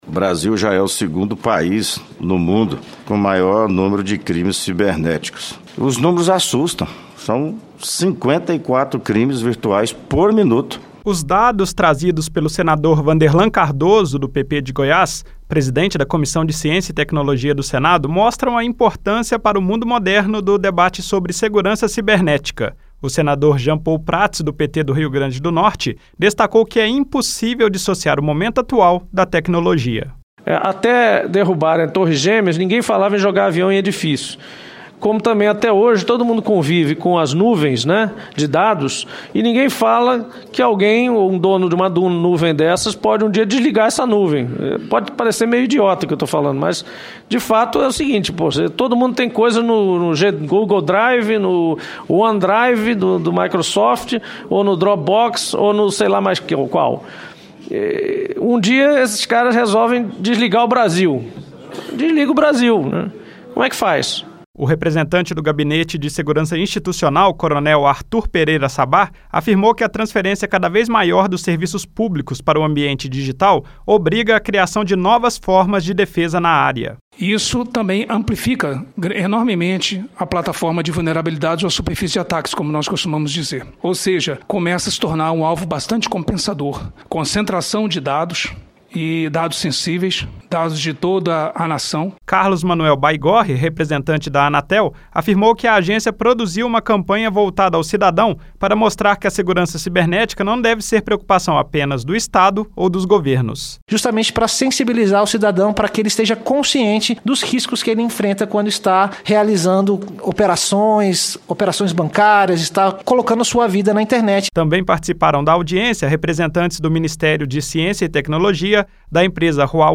A Comissão de Ciência e Tecnologia debateu a segurança cibernética do estado brasileiro. O senador Vanderlan Cardoso (PP-GO) lembrou que o Brasil é o segundo país do mundo em número de crimes virtuais. O senador Jean Paul Prates (PT-RN) destacou que decisões de grandes empresas de tecnologia podem afetar todo o país.